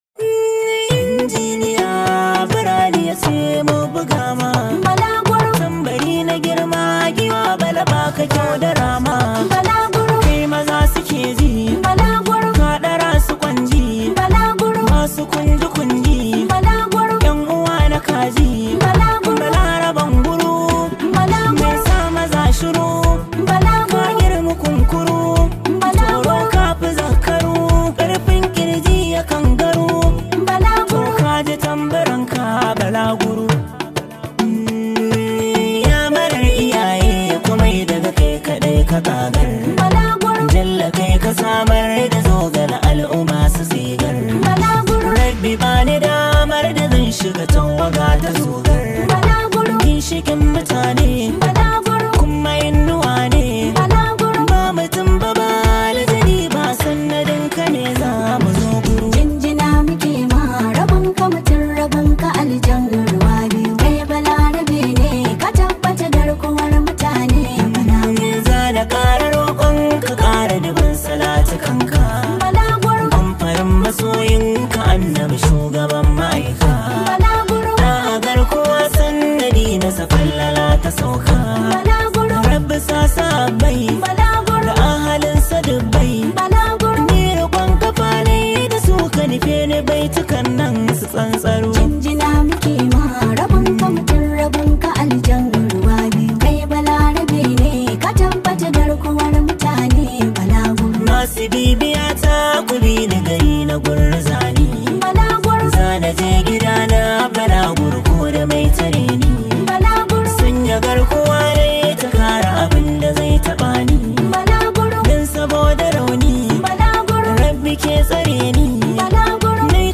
it comes with a lot of energy and positive Vibes
Hausa Music